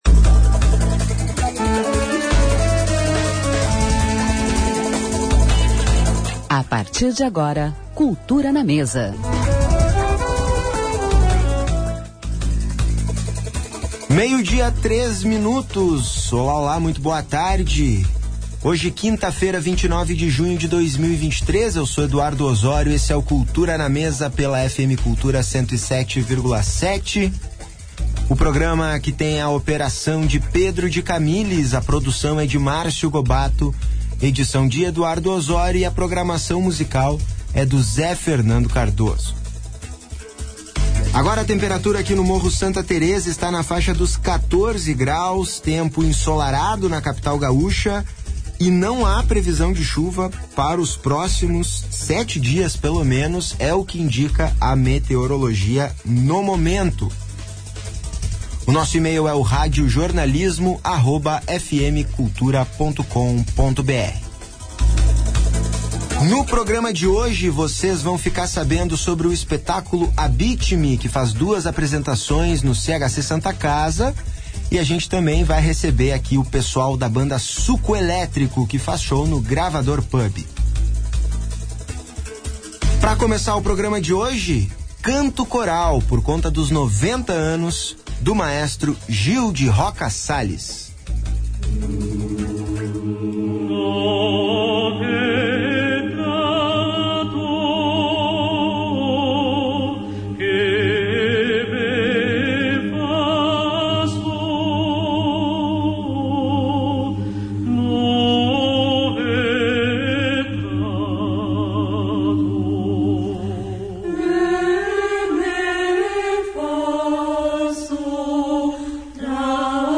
Entrevista e música ao vivo coma banda Suco Elétrico